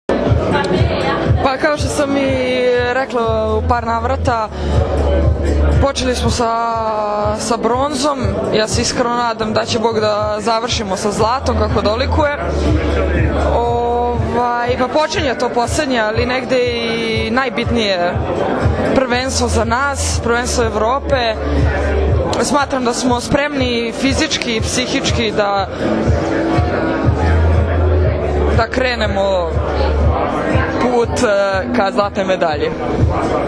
IZJAVA JOVANE STEVANOVIĆ